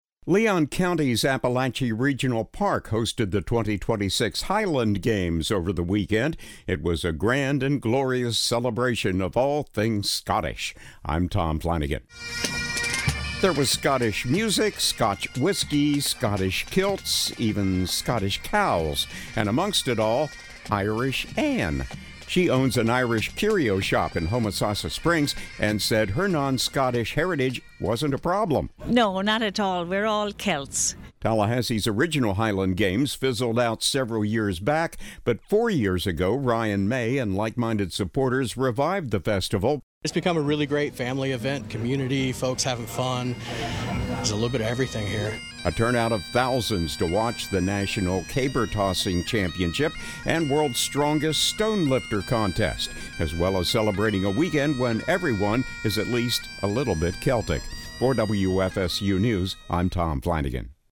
Tallahassee hosts the Fourth Annual Highland Games
It was a grand and glorious celebration of all things Scottish.
There was Scottish music, Scotch whiskey, Scottish kilts, even Scottish cows.